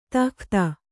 ♪ tahkhta